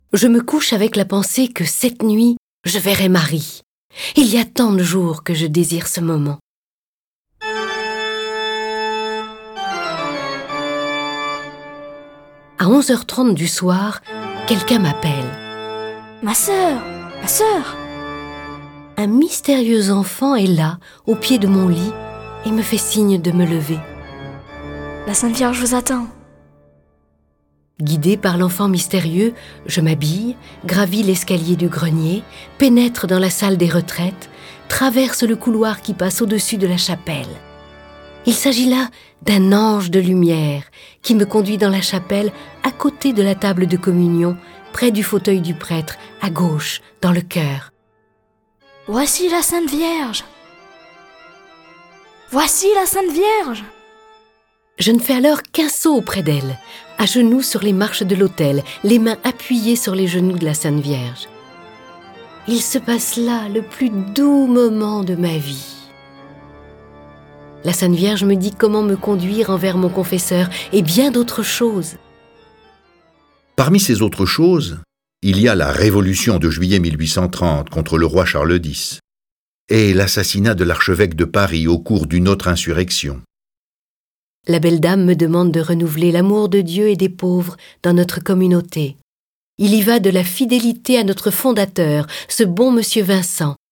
Cette version sonore de la vie de Catherine est animée par dix voix et accompagnée de plus de trente morceaux de musique classique.
Le récit et les dialogues sont illustrés avec les musiques de Bach, Charpentier, Chopin, Donizetti, Haendel, Haydn, Mahler, Mascagni, Mozart, Pachelbel, Pergolèse, Schumann, Telemann, Vivaldi.